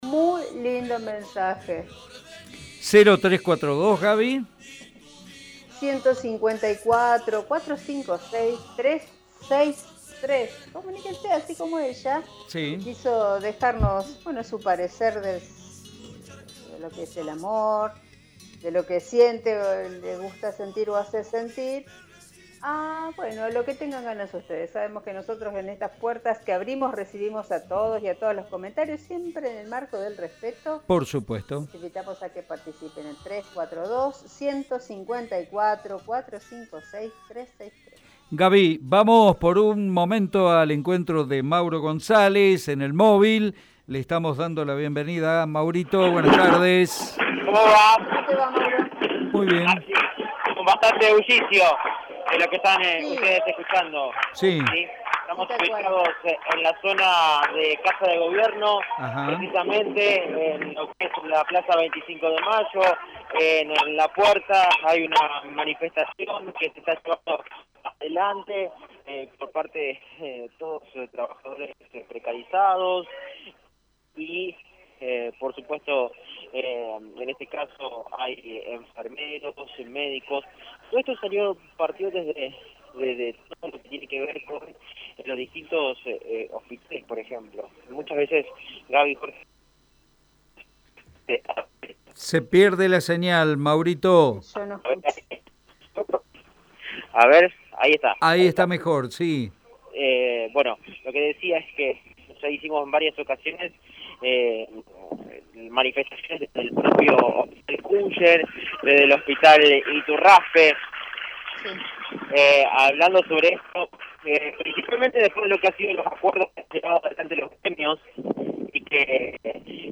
En tanto, el móvil de Radio EME dialogó con representantes de los profesionales de la salud sobre los reclamos.